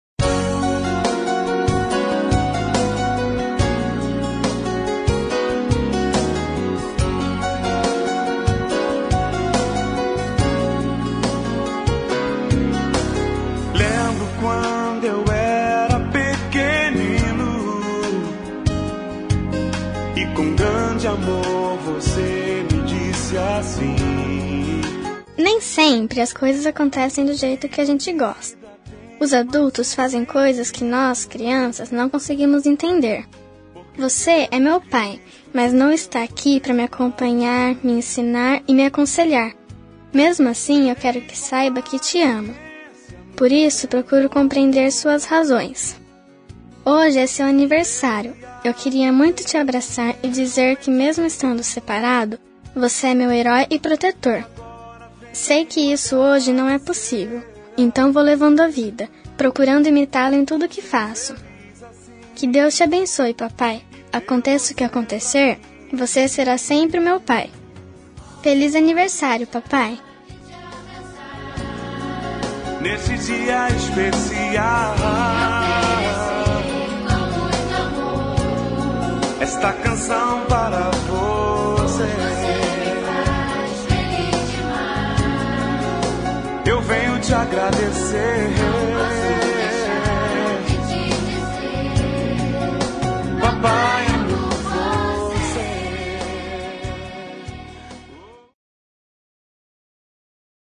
25702-infantil-pais-separados-fem.m4a